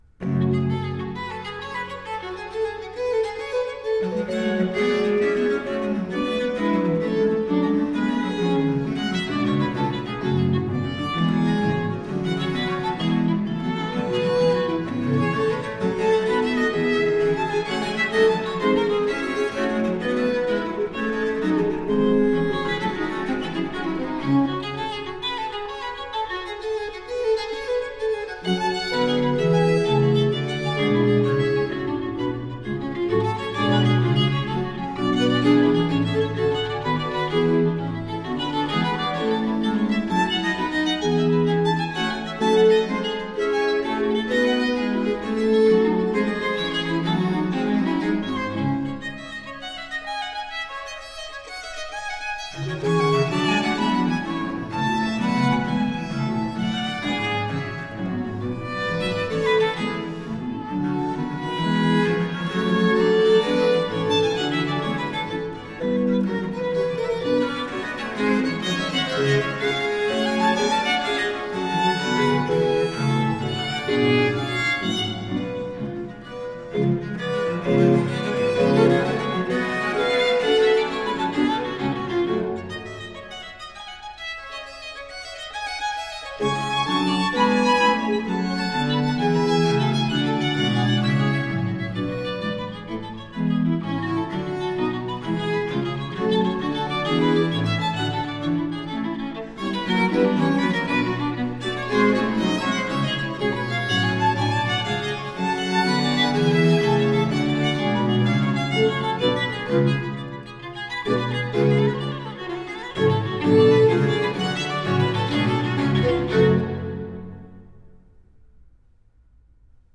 trio sonata in g minor
allegro